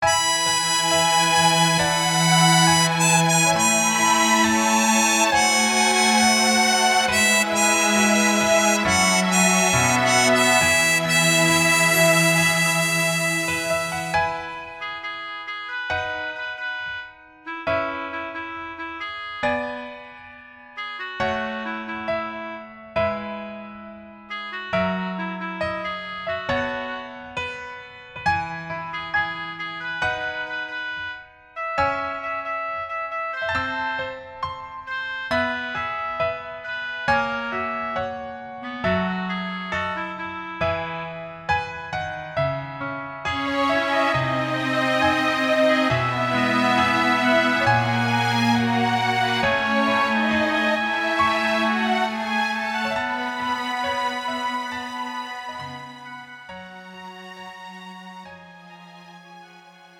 음정 여자키
장르 가요 구분 Pro MR